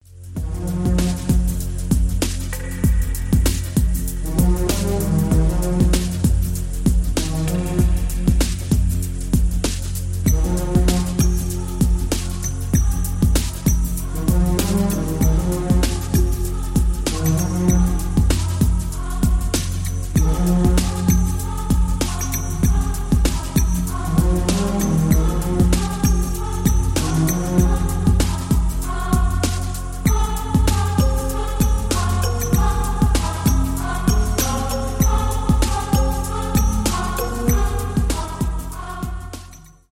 без слов , new age
индийские